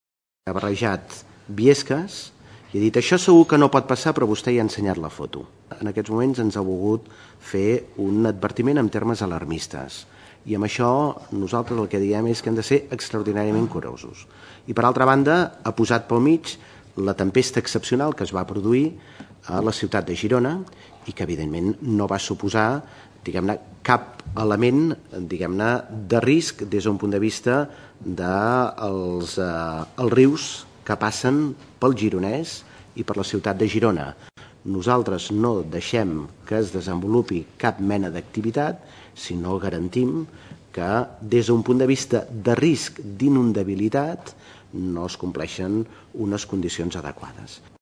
Josep Rull  on insistia en què es revisen els plans d’inundabilitat.